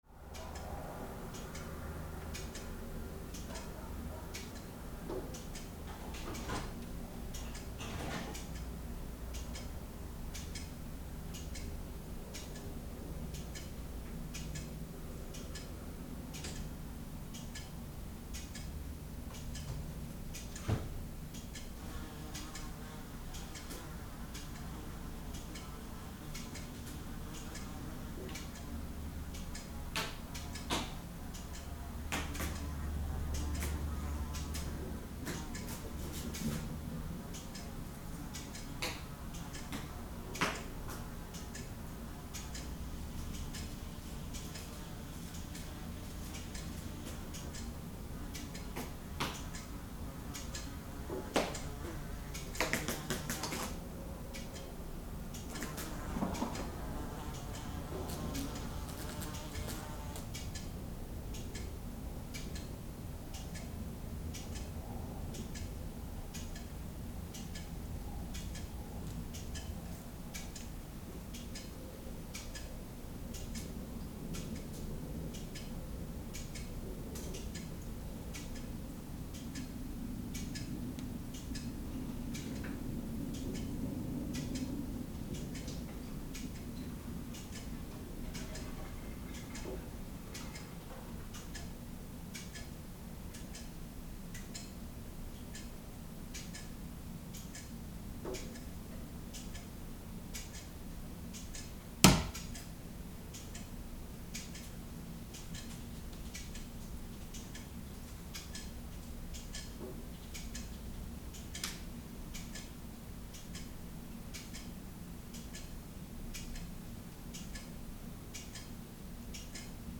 This recordings was made in 50m2 garage in the countryside. This recording contains mainly two ticking clocks, both sides of the mic rig, also a buzzing fly and a mouse jumping somewhere in the garage. Outside is a traffic in a distance
Audio samples goes through 80Hz HPF and normalized up to 0dB which increased the gain on MKH8040 about +16dB, NT1 about +14dB and LCT540S about +9dB *